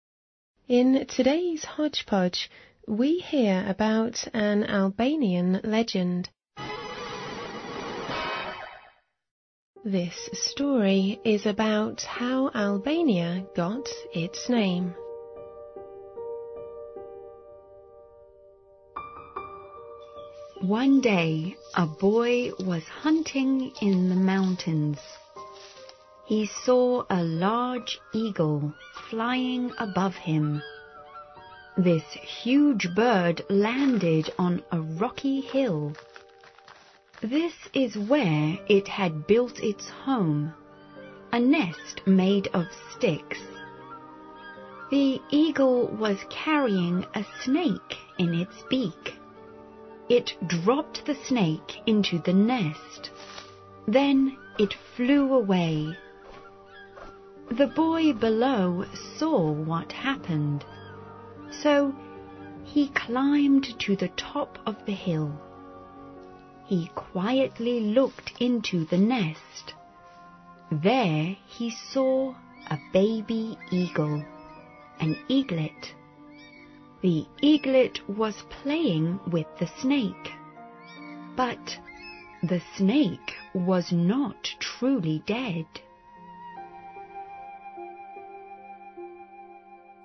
环球慢速英语 第77期:听众来信(5)